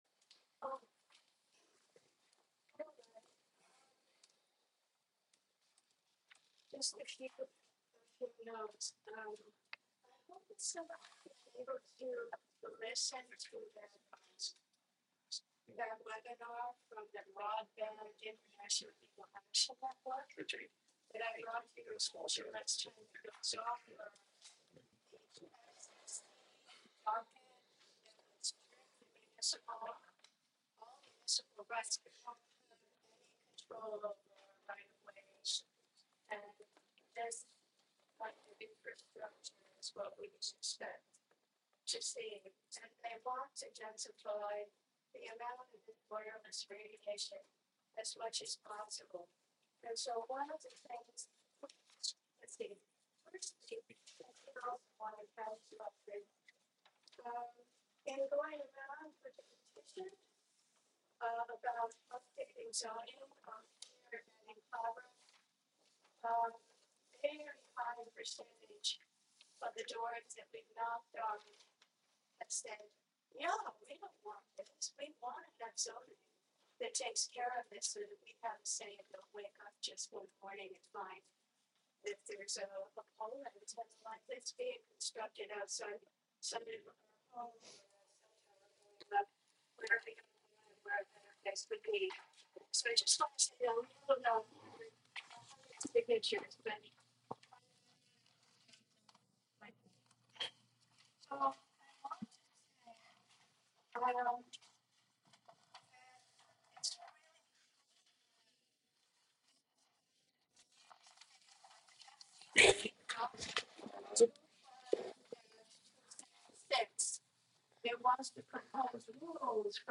Live from the Village of Philmont: Planning Board Meeting (Audio)